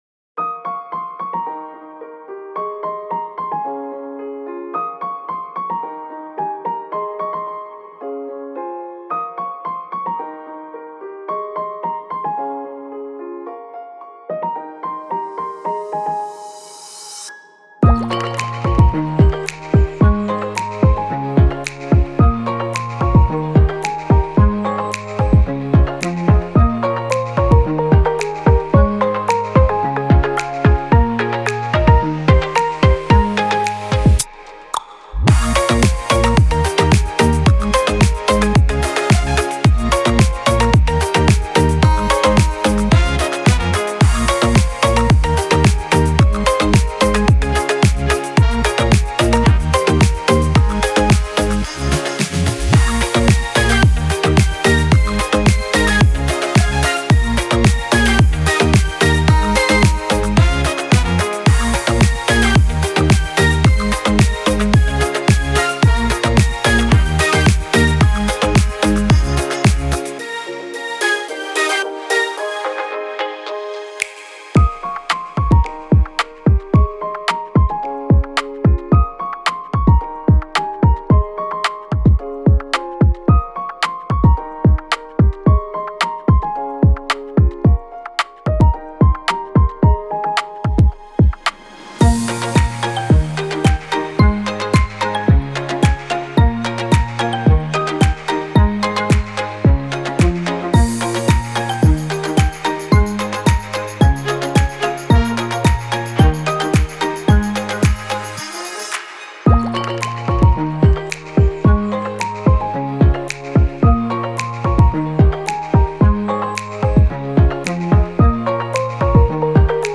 موسیقی بی کلام الکترونیک پاپ موسیقی بی کلام انرژی مثبت